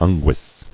[un·guis]